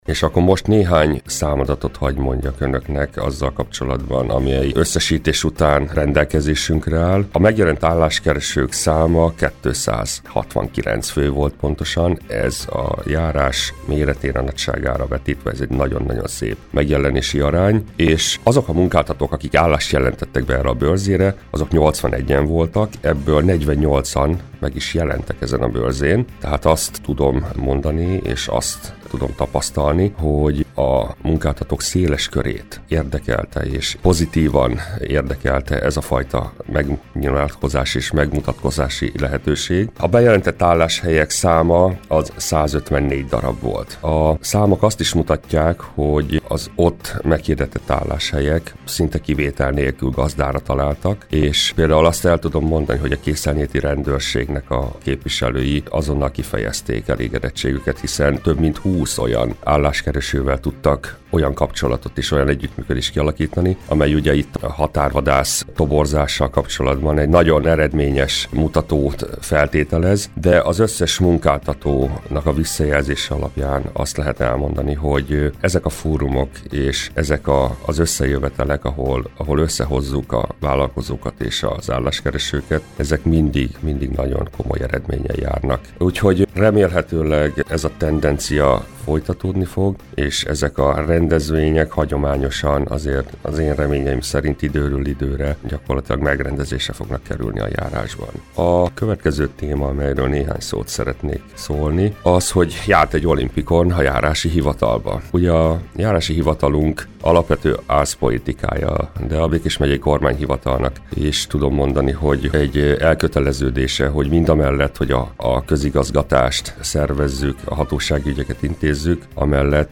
Dr. Pacsika György, a Gyomaendrődi Járási Hivatal vezetője volt a Körös Hírcentrum stúdiójának vendége. Vele beszélgetett tudósítónk a Gyomaendrődi járás elmúlt hónapjáról.